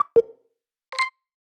incoming_transmission.wav